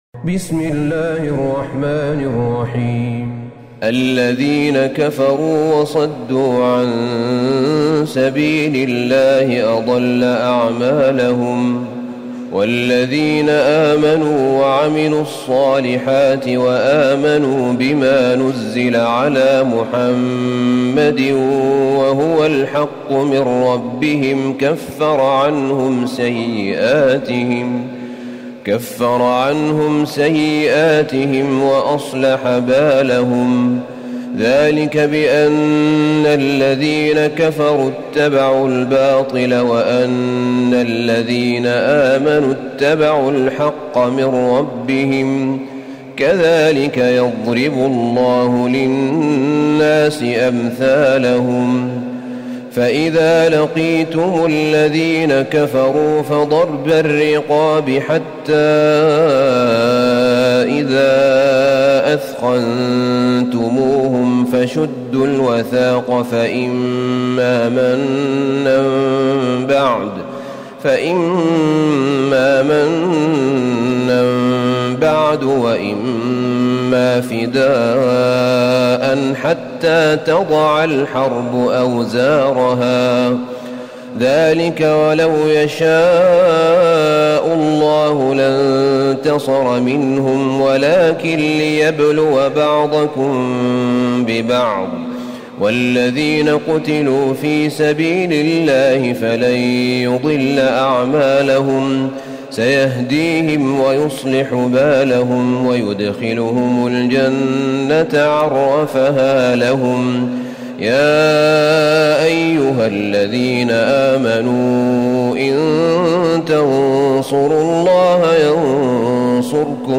سورة محمد Surat Muhammad > مصحف الشيخ أحمد بن طالب بن حميد من الحرم النبوي > المصحف - تلاوات الحرمين